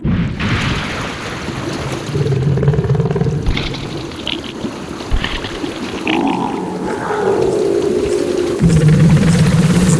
Звук коллапса химической аномалии